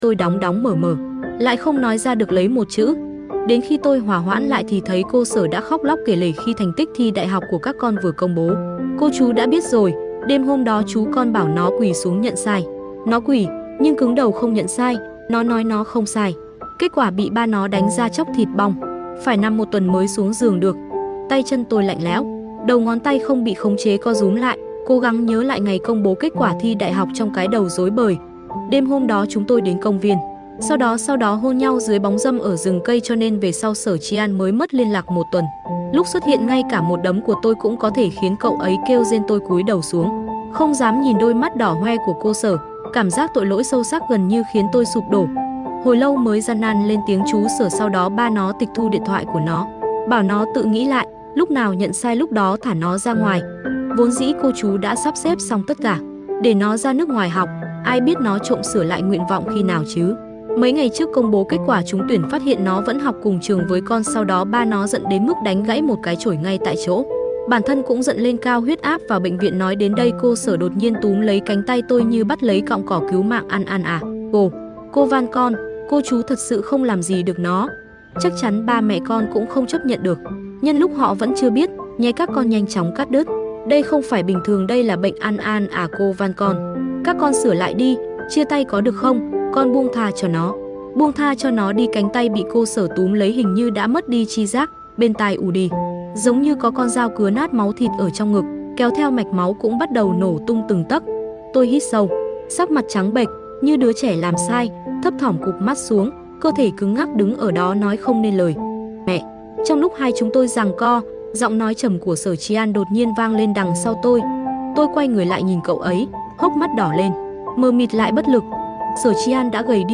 TRUYỆN AUDIO | Mai Táng Sound Effects Free Download